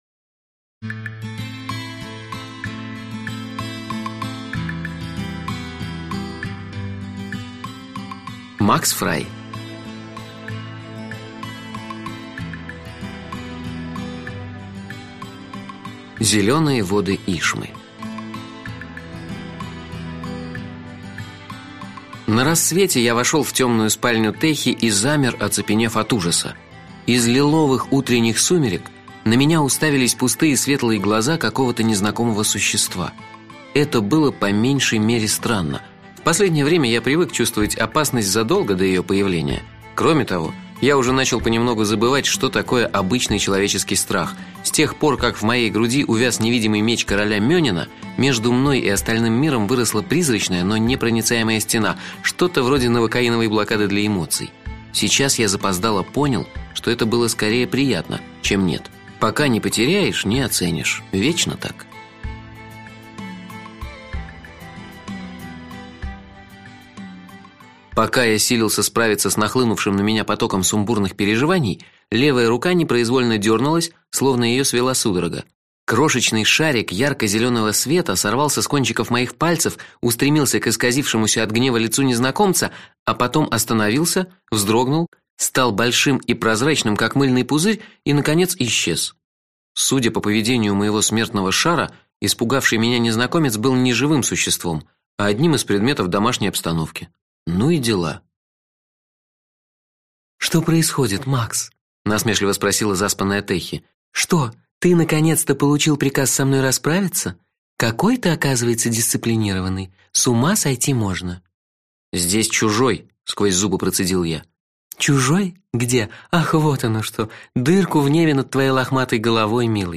Аудиокнига Наваждения - купить, скачать и слушать онлайн | КнигоПоиск